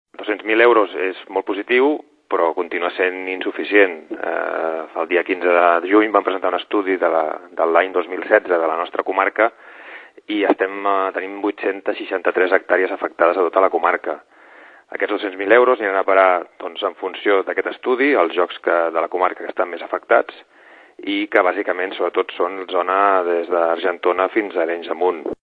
Josep Triadó és conseller de medi ambient del Consell Comarcal.